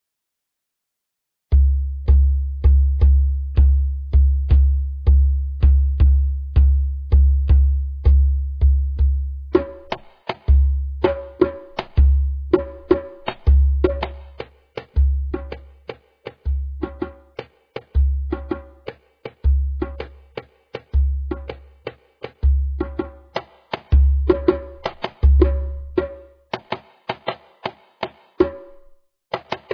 African Tribal Drums
Royalty free African tribal loops.
32kbps-Tribal-Drum-Loop.mp3